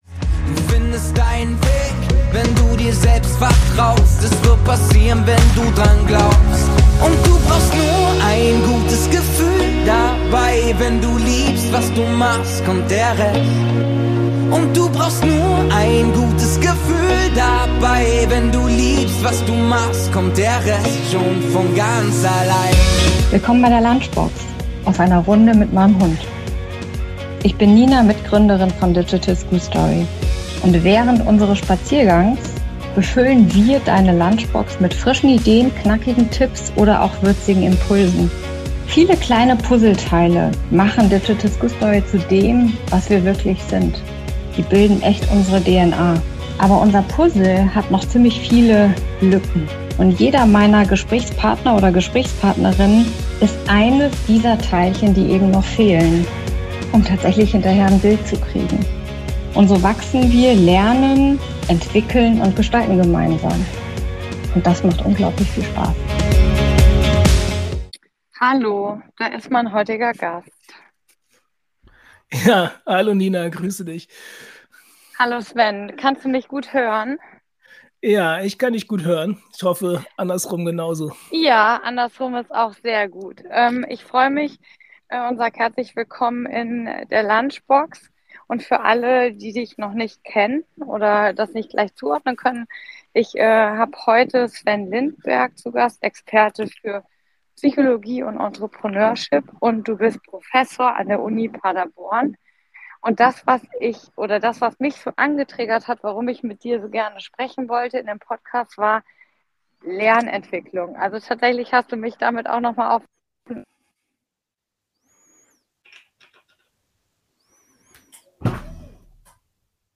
Während unseres Spaziergangs haben wir Deine Lunchbox befüllt mit Themen rund um Lernentwicklung, digitale Methoden und wir immer wieder neue Zugänge brauchen, um Diskrepanzen zu überbrücken.